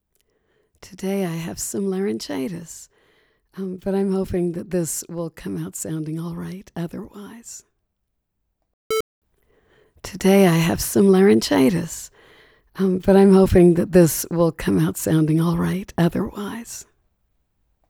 ACX Dang that noise floor
Zoom H2 sample wav laryngitis.wav (1.47 MiB) Not downloaded yet
[ I’m nitpicking now: there is a metallic resonance on “hoping that this”, like the springs on those mic boom arms ringing ]